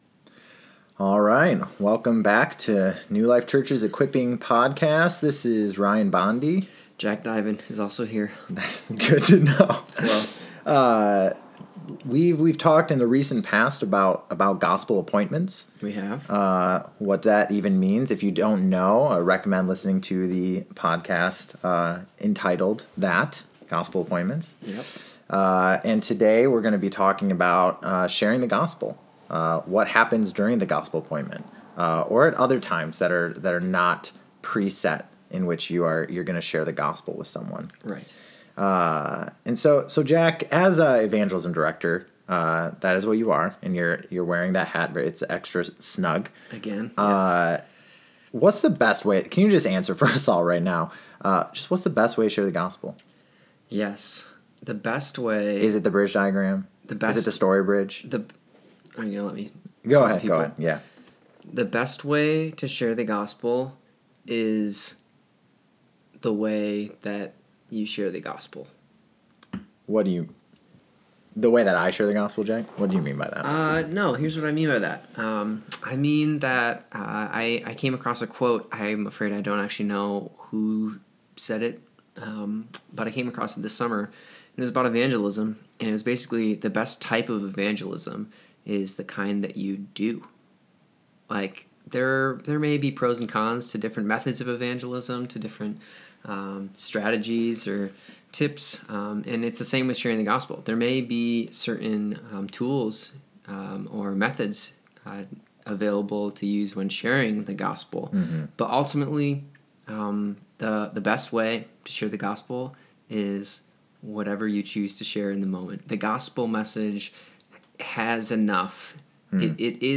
Discussion on sharing the Gospel